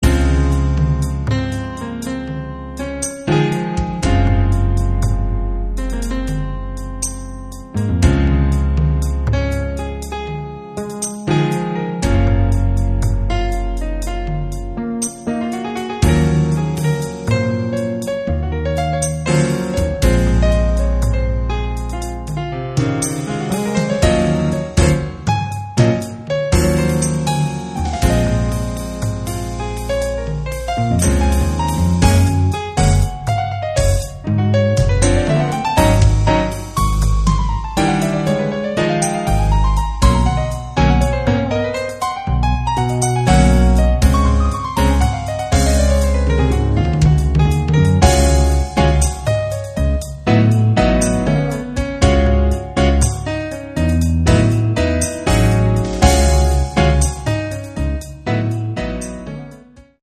(Jazz)